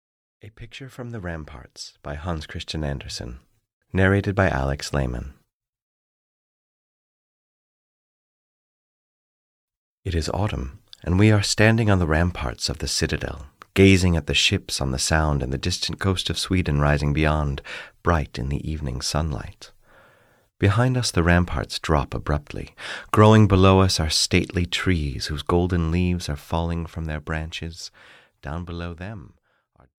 A Picture from the Ramparts (EN) audiokniha
Ukázka z knihy